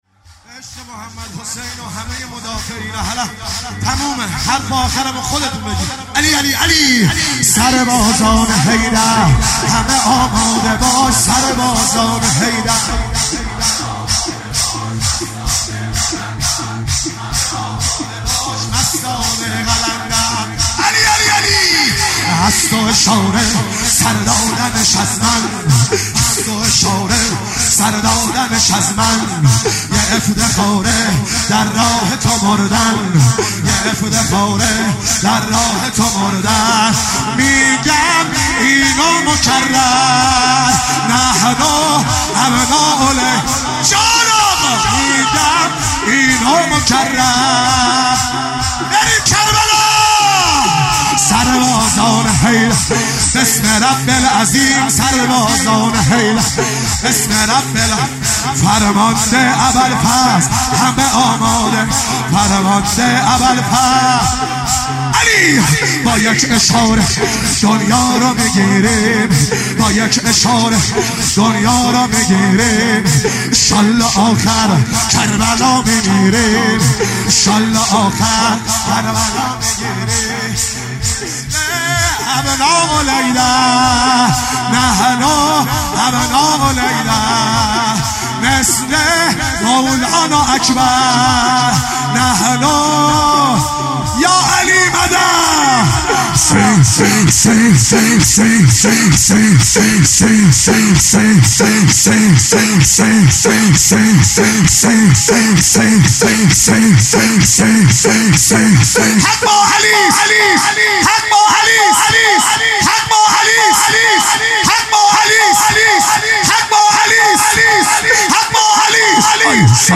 شب چهارم محرم 97 - شور - سربازان حیدر